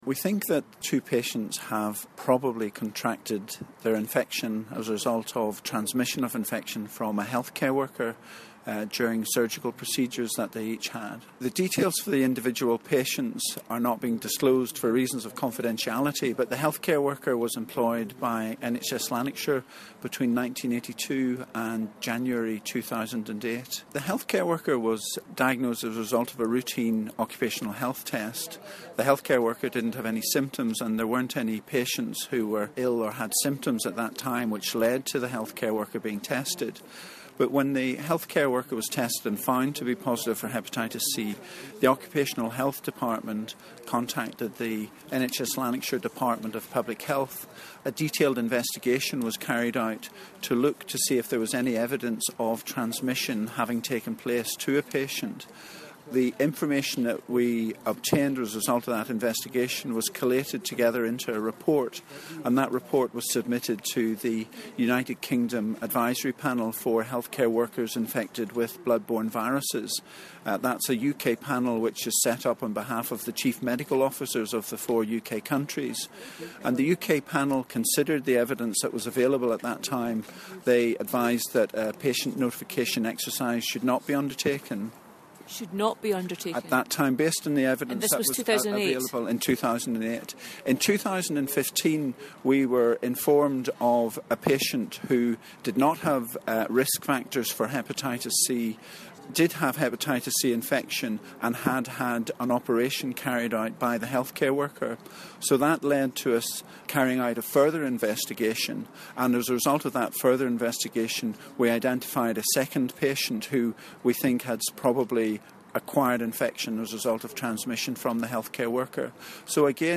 on a hepatitis C scare which could affect over 8,300 patients (interviewed